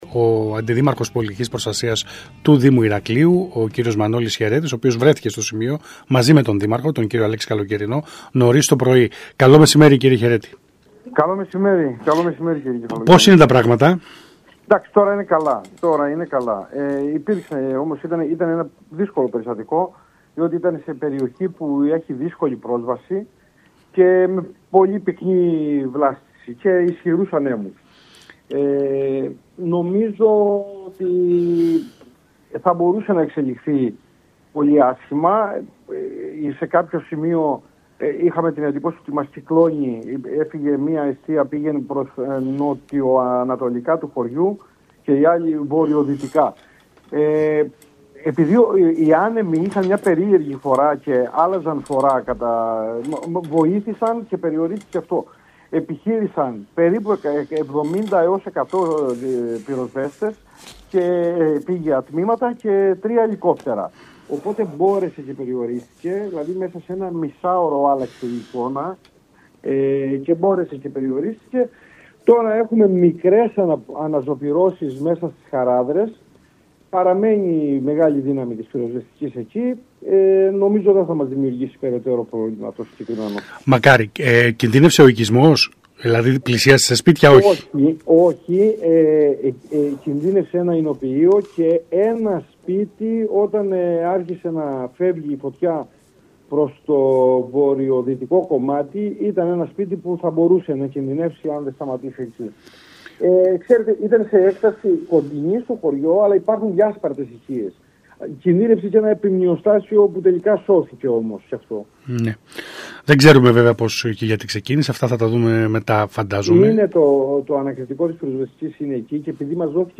Σύμφωνα με τα όσα δήλωσε στον ΣΚΑΪ Κρήτης 92.1
Στο σημείο μετέβησαν ο Δήμαρχος Αλέξης Καλοκαιρινός και ο Αντιδήμαρχος Πολιτικής Προστασίας Μανόλης Χαιρέτης.
Ακούστε τον κ. Χαιρέτη: